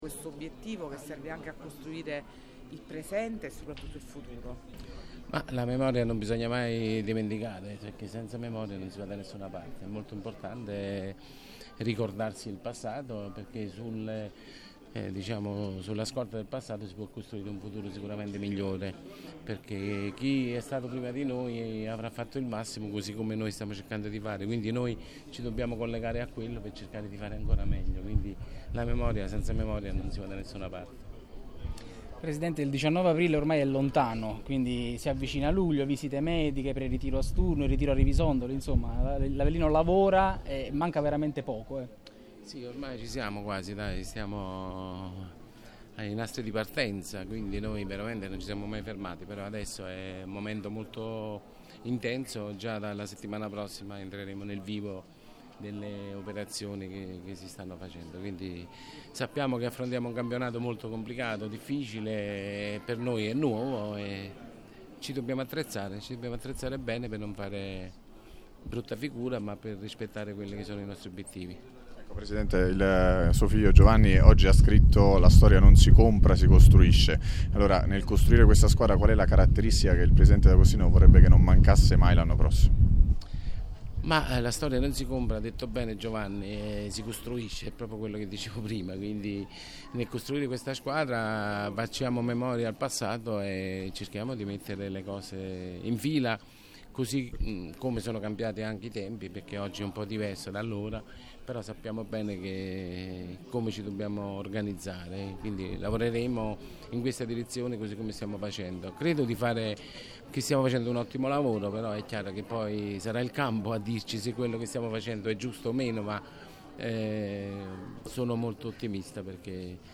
In occasione della mostra organizzata dall’Archivio di Stato di Avellino